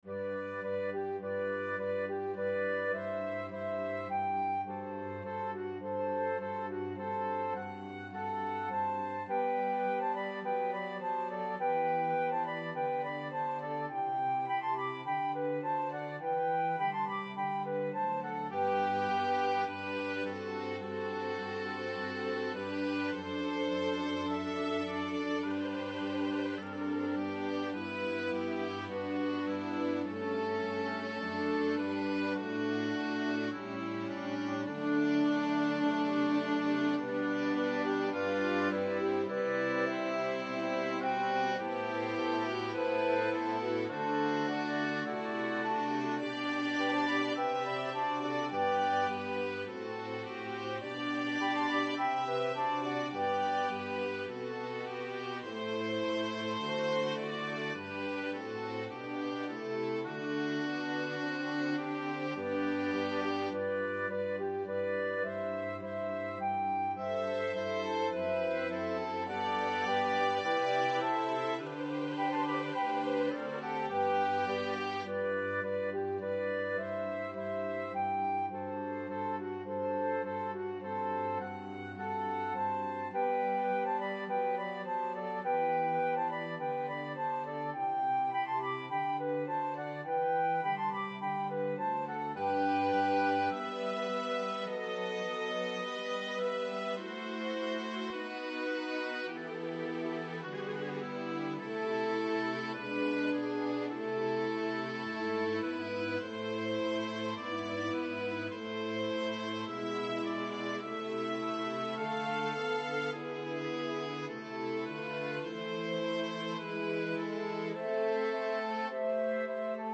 Orchestration
2 Flutes, Oboe, 2 Clarinets in Bb
Strings (Violin 1, Violin 2, Viola or Violin 3, Cello/Bass)